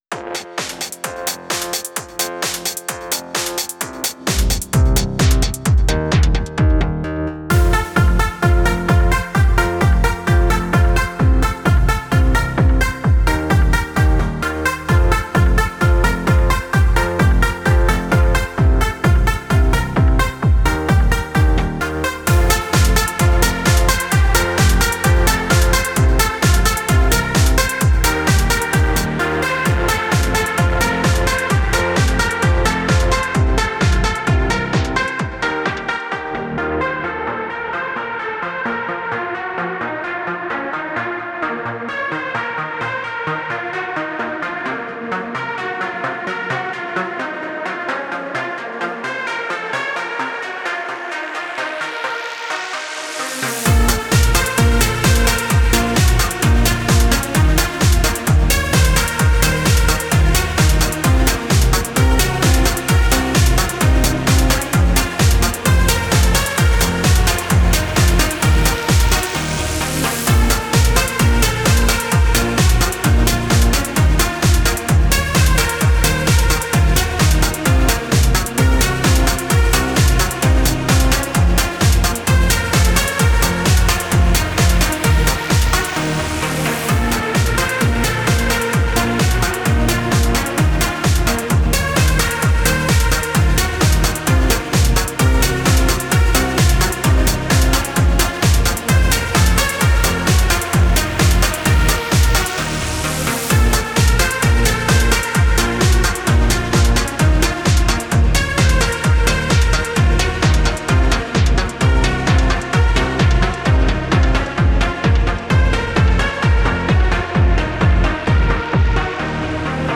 BEST ELECTRO G-Q (39)